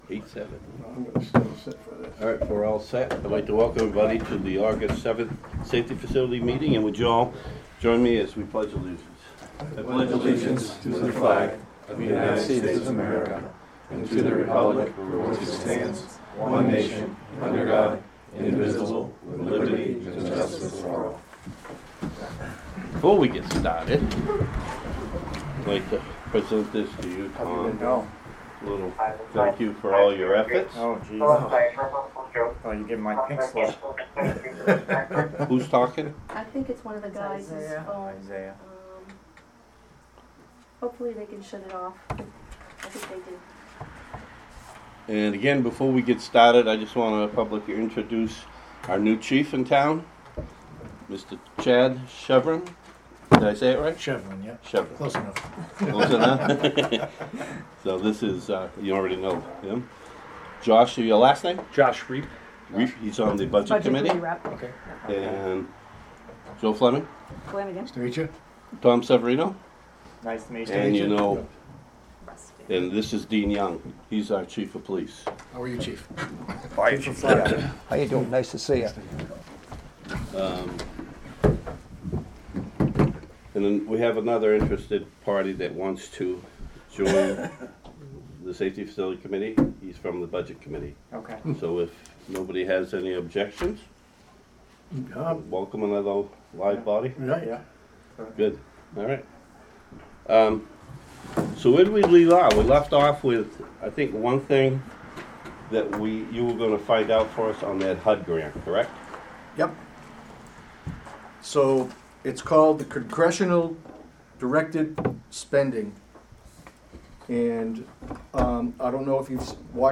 Audio recordings of committee and board meetings.
Safety Facility Committee Meeting